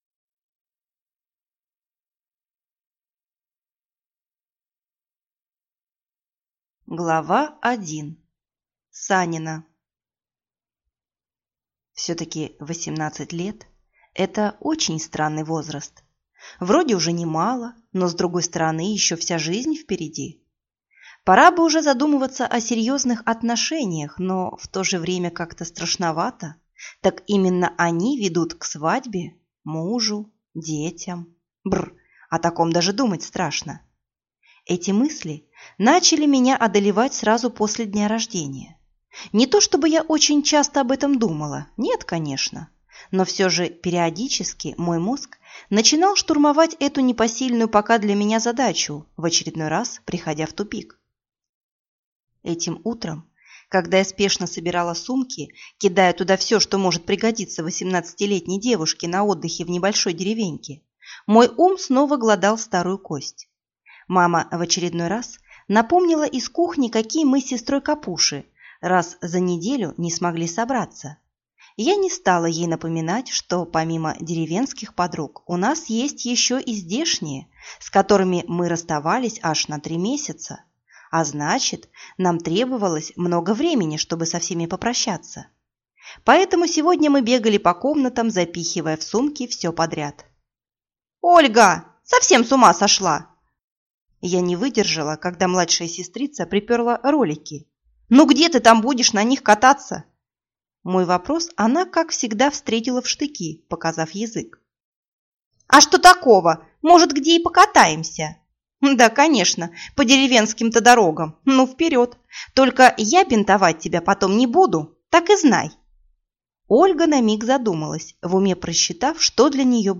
Аудиокнига Повелители Снов. Предание | Библиотека аудиокниг